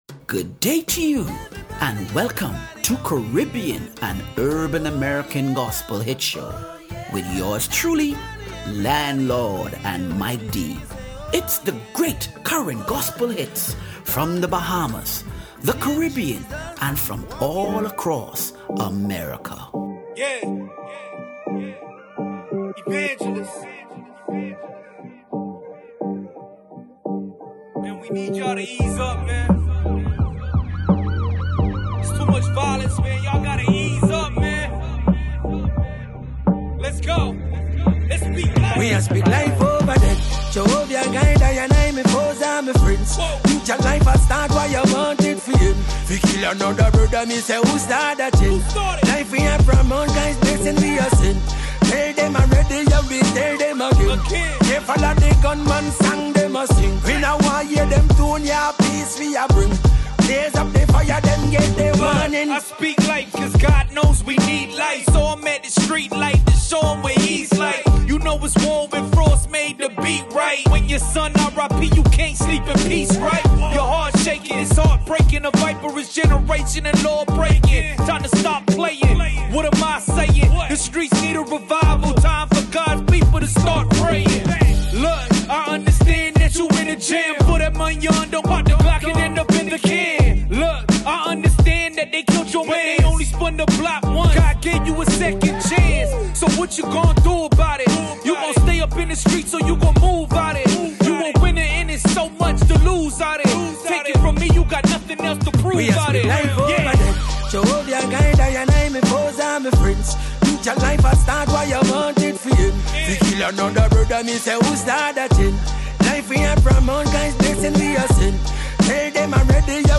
Caribbean and Urban American Gospel Hits Caribbean and Urban American Gospel Hits - November 23 2025 Nov 24 2025 | 00:29:46 1x 00:00 / 00:29:46 Subscribe Share Spotify RSS Feed Share Link Embed